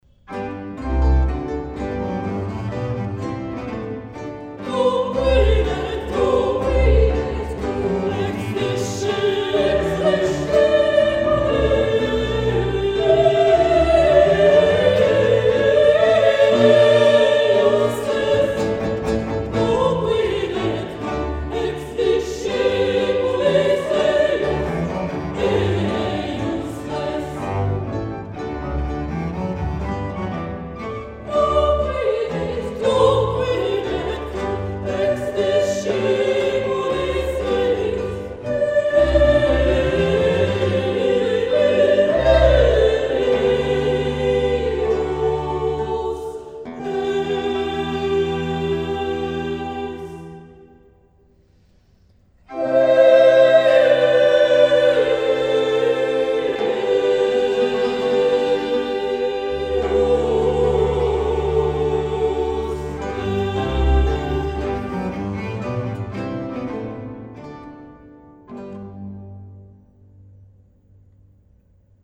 Beaucoup de sentiments sans tomber dans un sentimentalisme exacerbé.